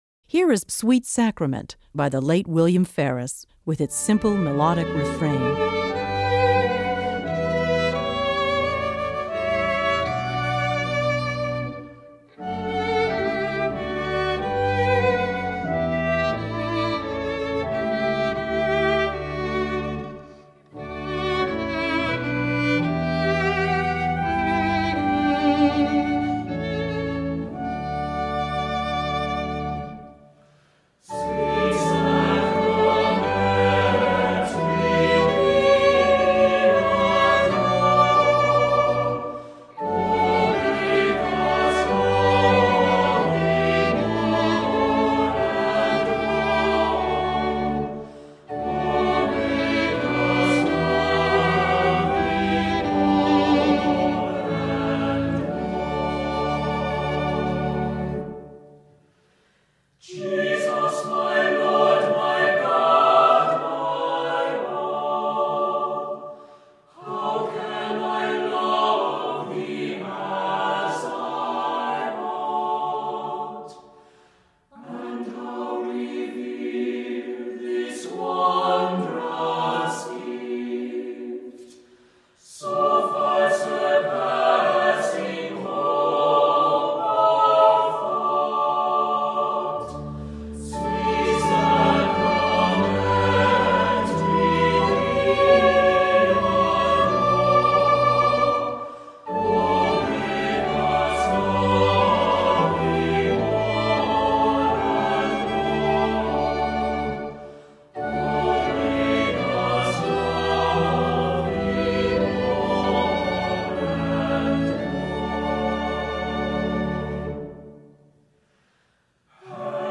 Voicing: Cantor,Assembly